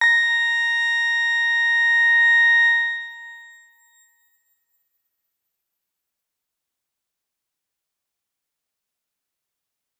X_Grain-A#5-pp.wav